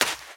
High Quality Footsteps
STEPS Sand, Run 19.wav